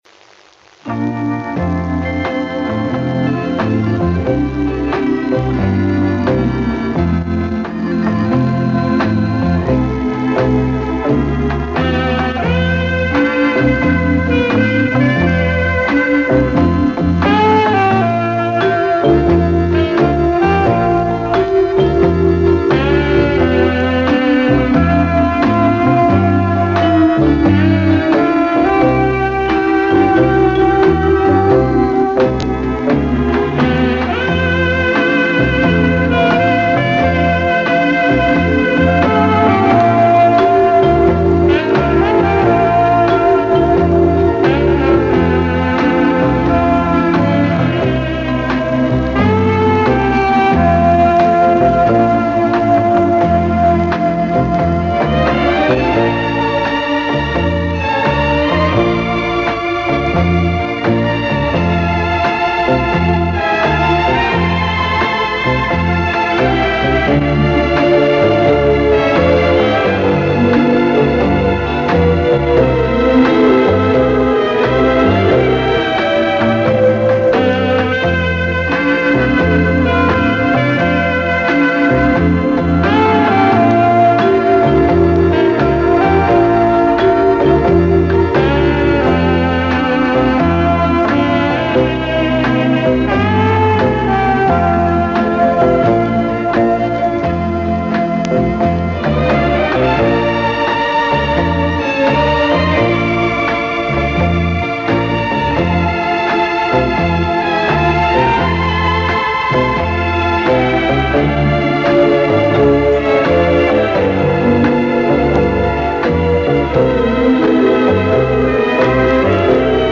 Интересный инструментал!